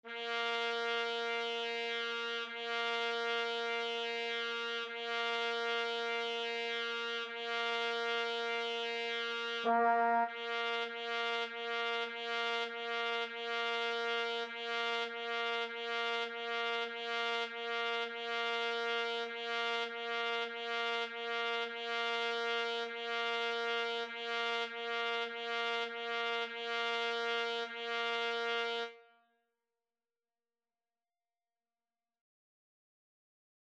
4/4 (View more 4/4 Music)
Bb4-Bb4
Trumpet  (View more Beginners Trumpet Music)
Classical (View more Classical Trumpet Music)